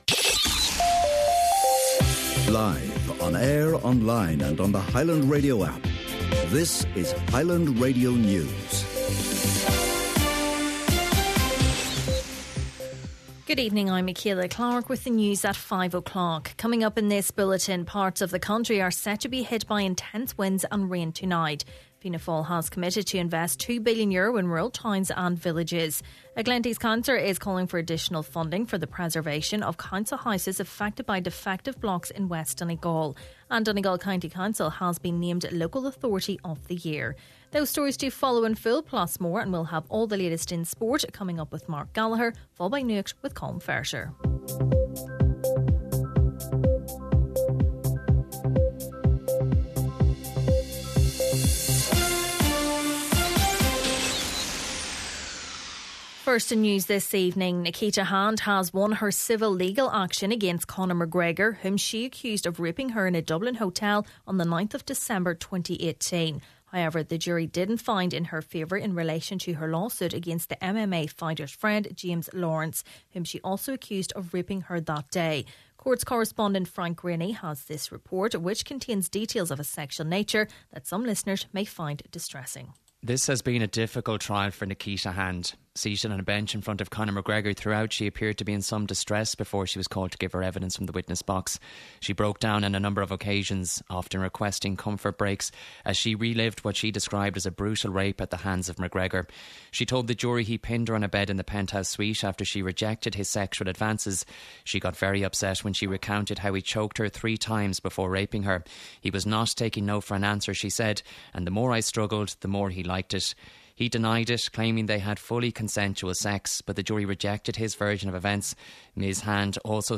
Main Evening News, Sport, Nuacht and Obituaries – Friday, November 22nd